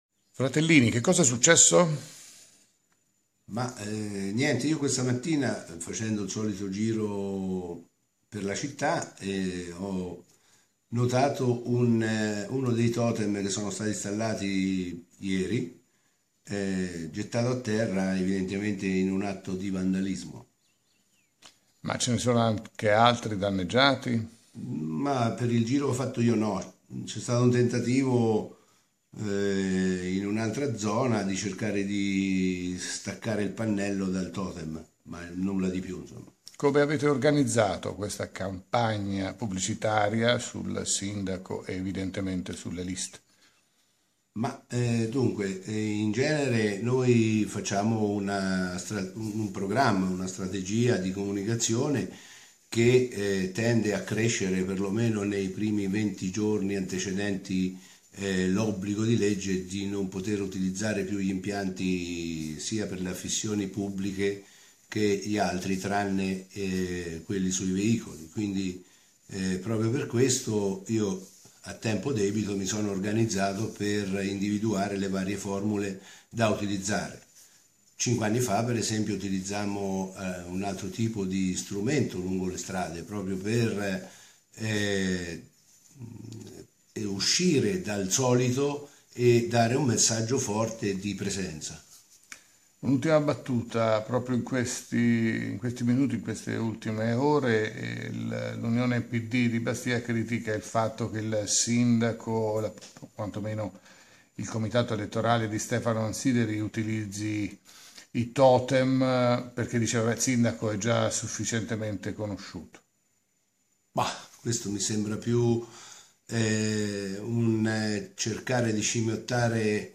La nostra redazione è andata intervistare il vicesindaco della città Francesco Fratellini per capire qualcosa in più e come mai si sia verificato un fatto del genere e poco elegante.
totem_abbattuto_intervista_fratellini.mp3